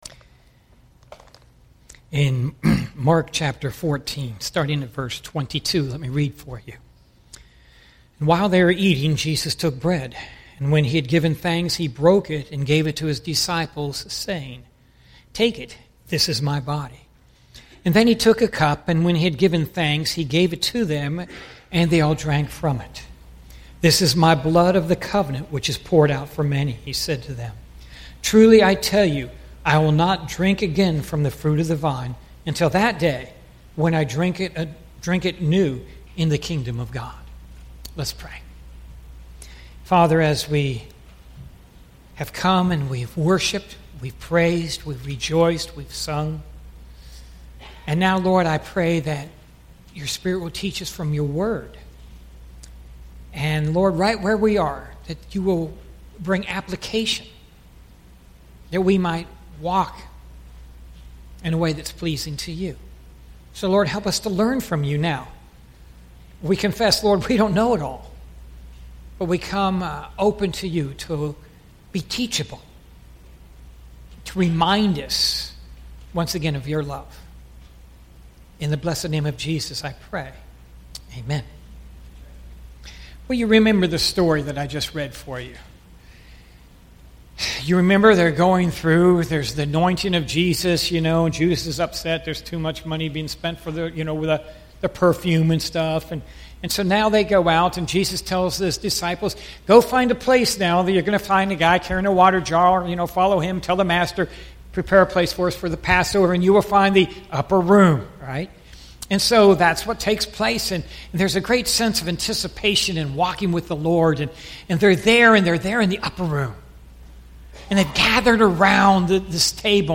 Sermons | Parkland Baptist Church